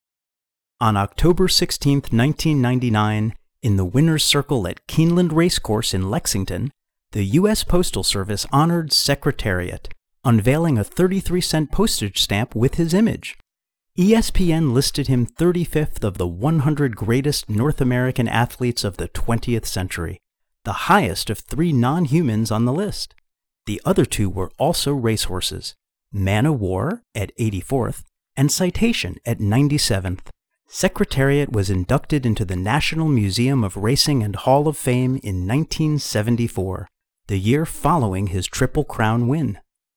Engaging, Authentic, Articulate Storyteller
Racehorse Athletes (audiobook)
General (Standard) American, Brooklyn
Middle Aged